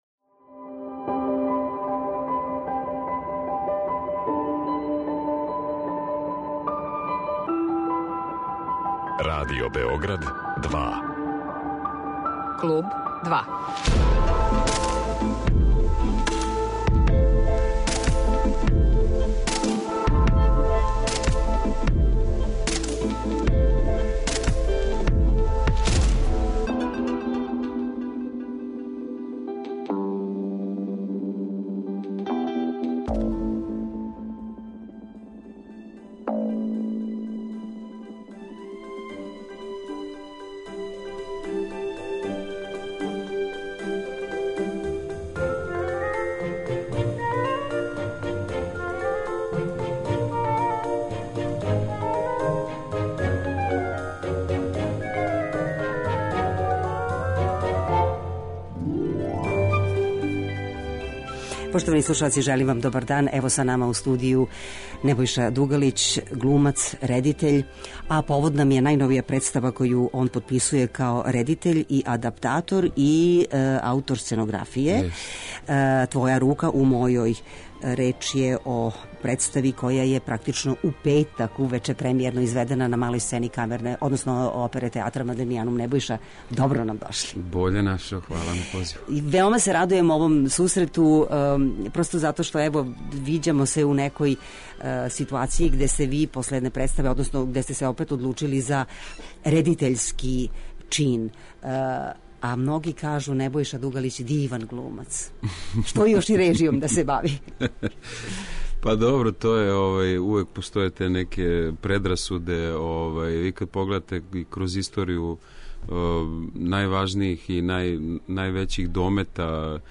Гост емисије је Небојша Дугалић, глумац и редитељ.